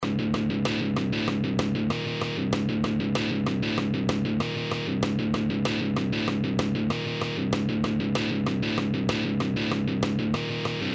This one is Dropped C Tuning.
Hetfield’s down picking is on full display in the song, driving the relentless riffing that propels the track forward.